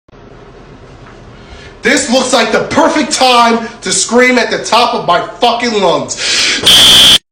Sound Effects
Stupid Goofy Ahh Sounds